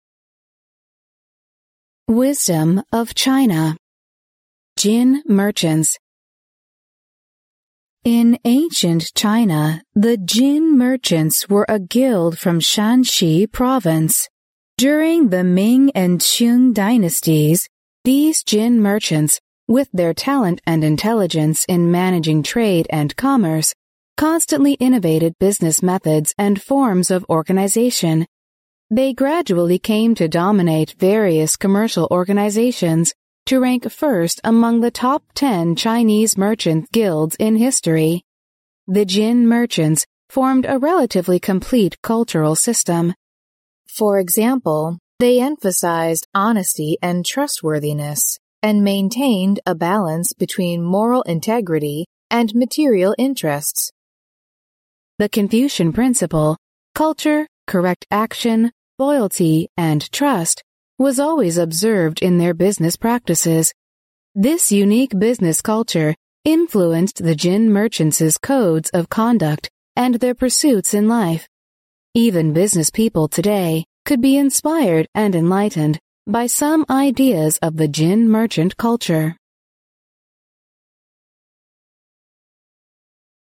第一册英语单词朗读录音